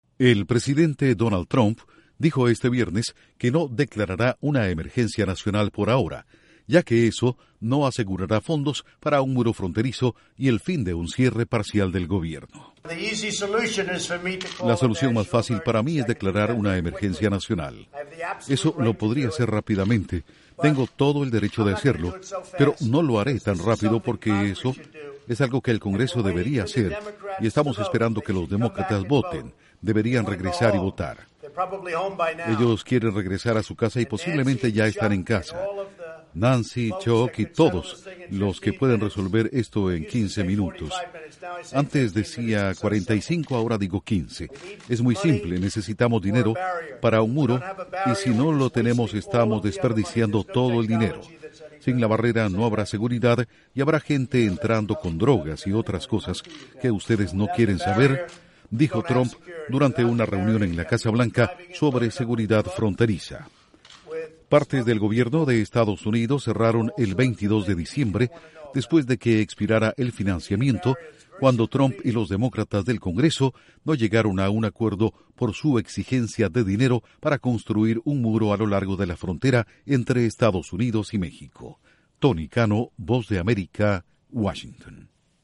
Duración: 1:25 2 audios de Donald Trump/Presidente EE.UU.